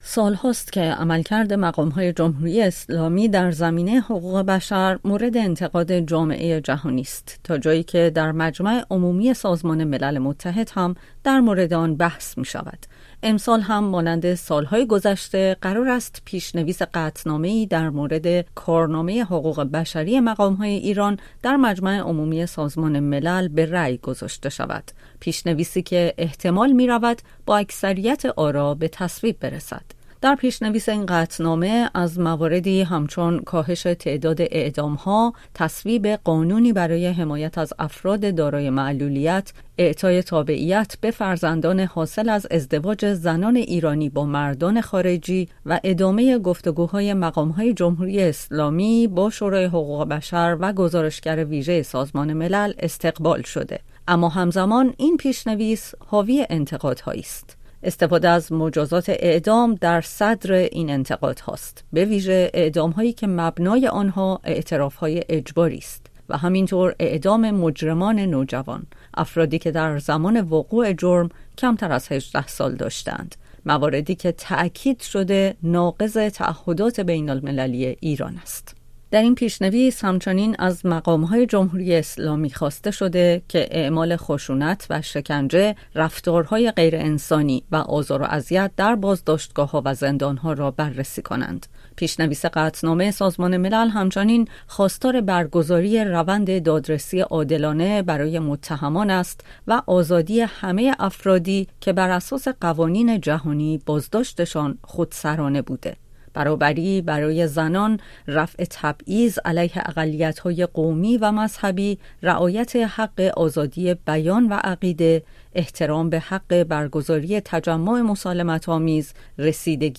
جزئیات بیشتر در گزارشی